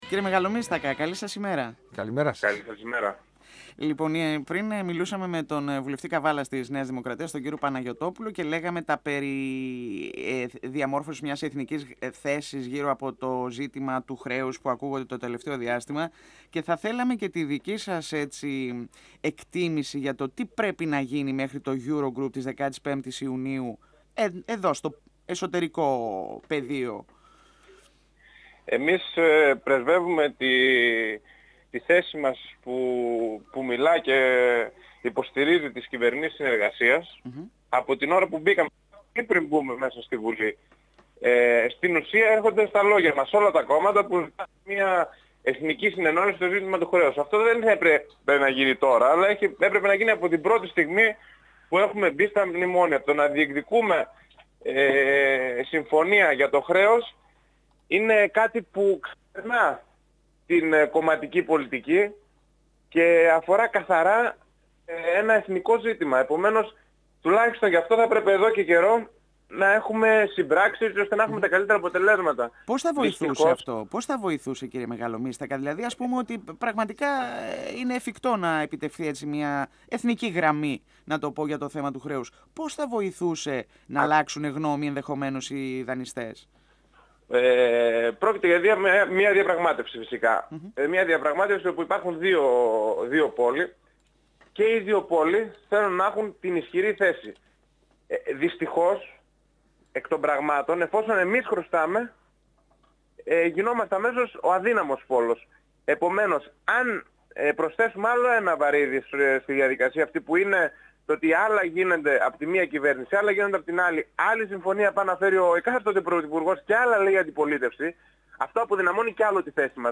25Μαϊ2017 – Ο βουλευτής Σερρών της Ένωσης Κεντρώων Αναστάσιος Μεγαλομύστακας στον 102 fm της ΕΡΤ3